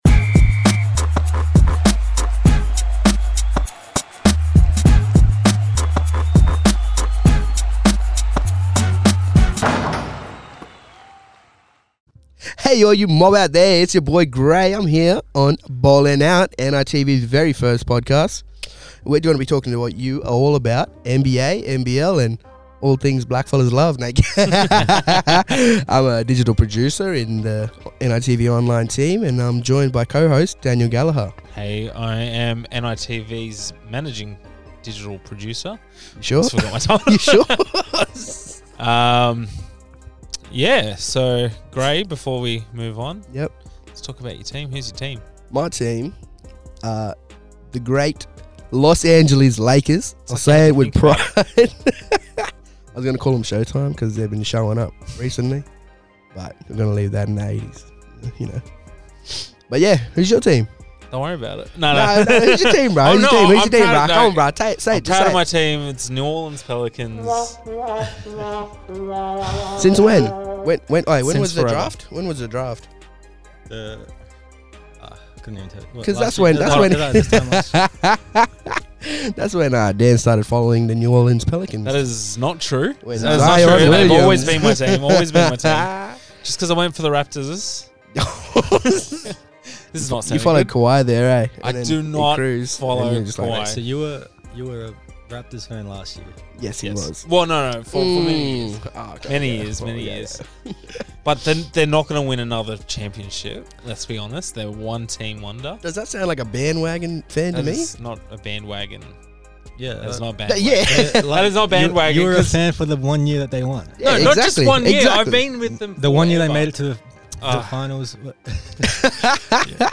NITV’s new podcast Ballin Out is a lighthearted yarn on all things NBA and NBL.